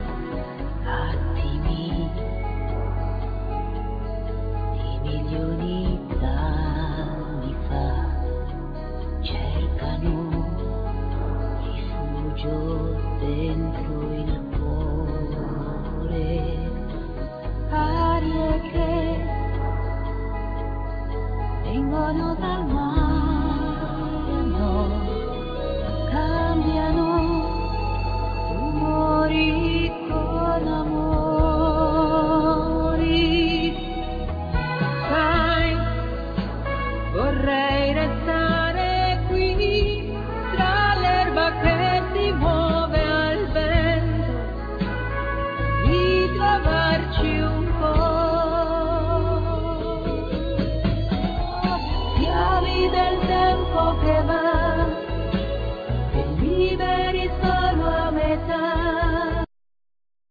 Vocal
Bass
Trumpet
Guitars